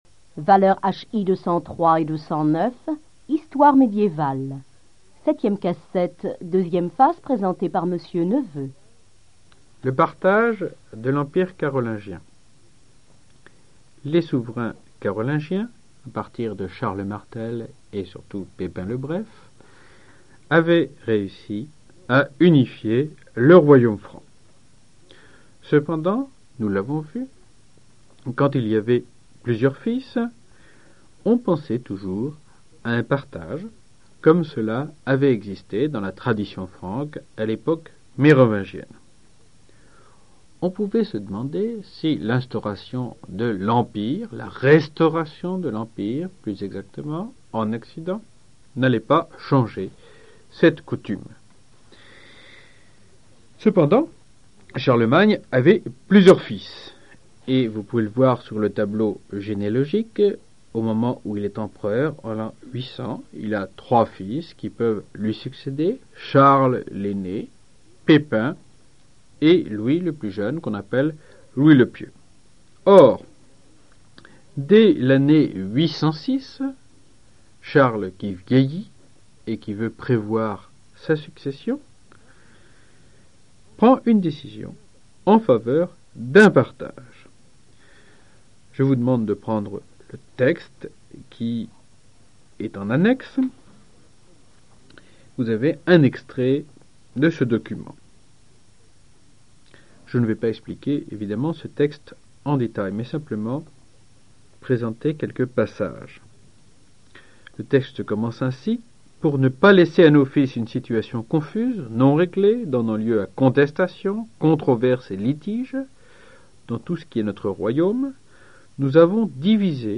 Archives cours audio 1987-1988